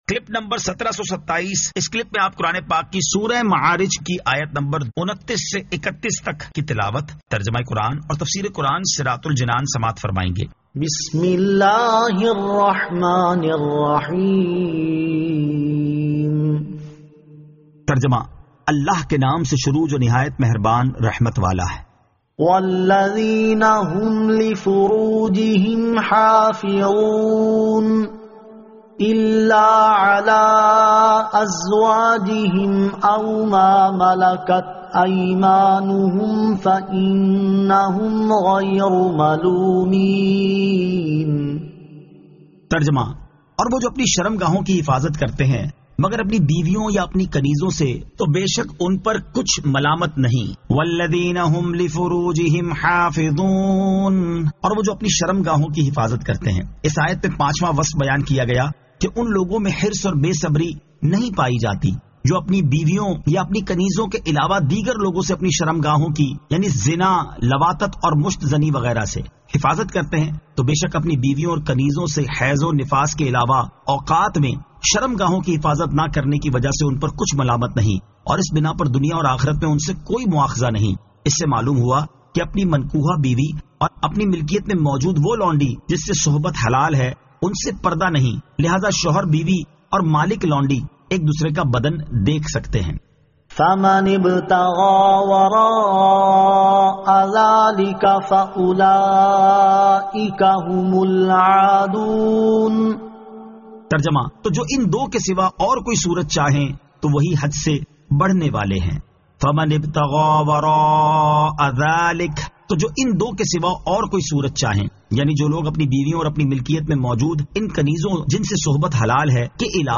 Surah Al-Ma'arij 29 To 31 Tilawat , Tarjama , Tafseer